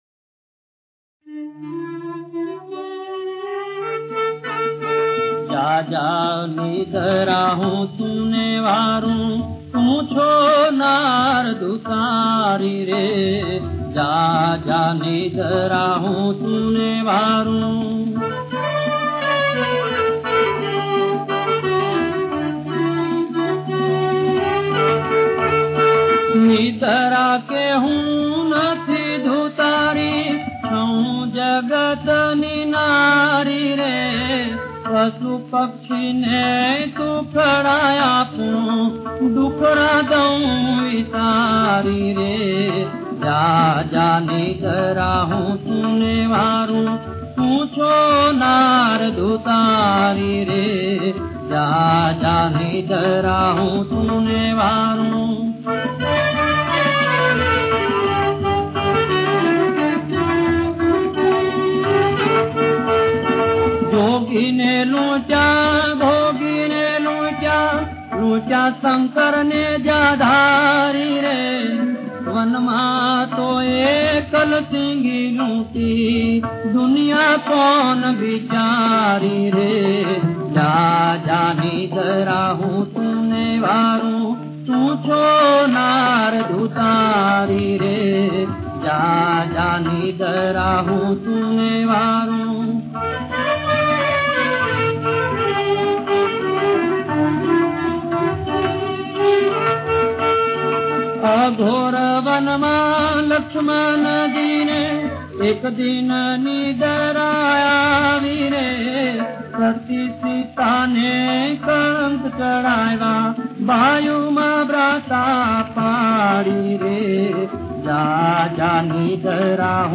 સંતવાણી